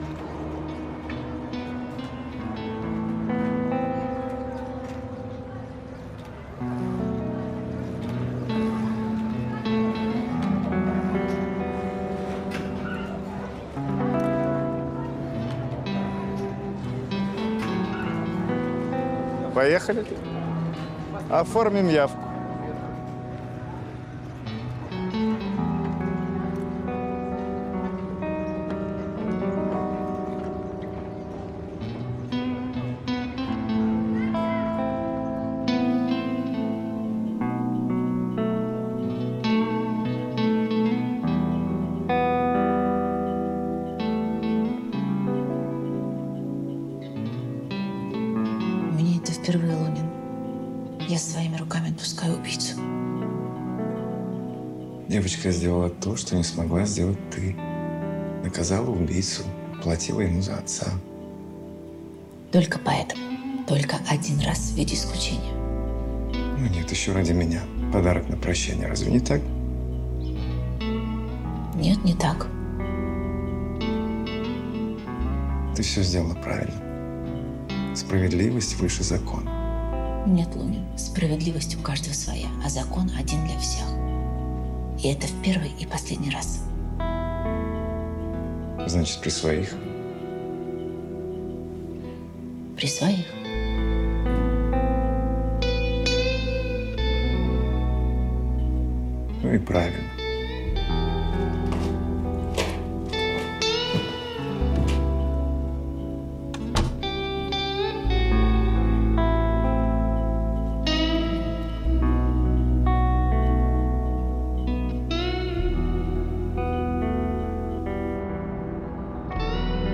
Фортепиано не услышал, вроде бы это гитарный перебор.